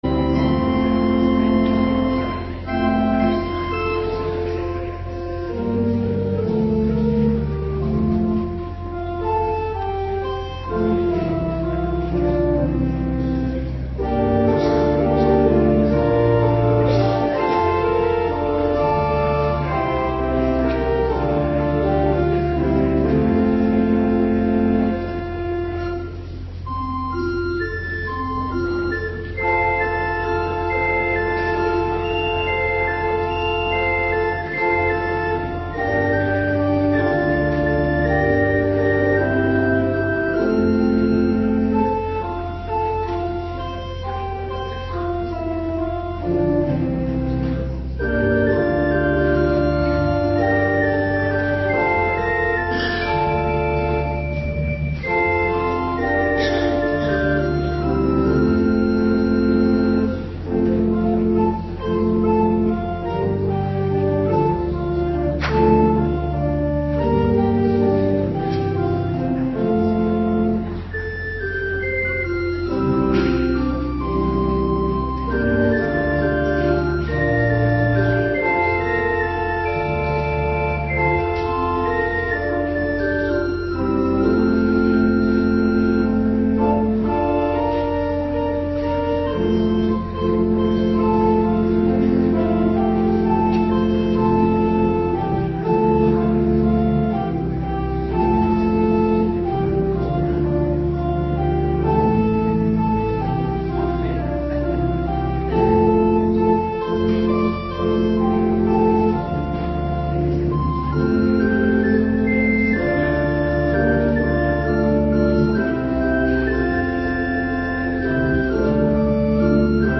Avonddienst 5 april 2026